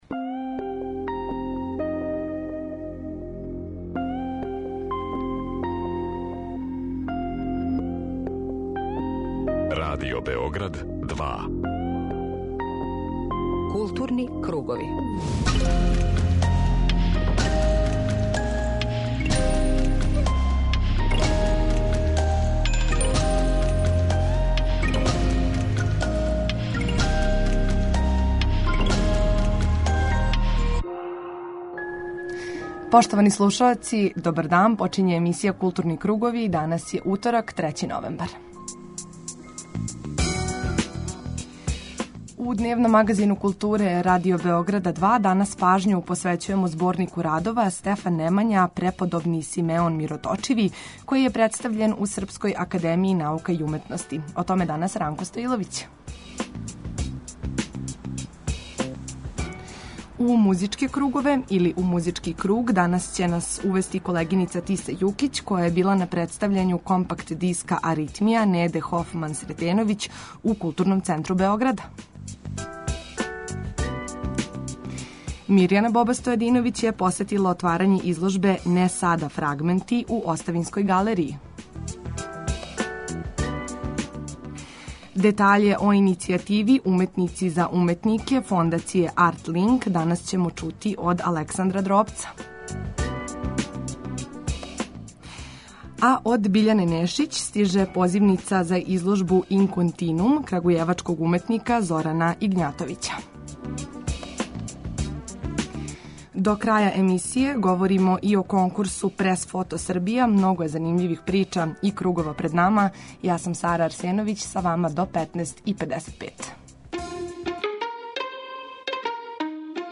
Централна културно-уметничка емисија Радио Београда 2.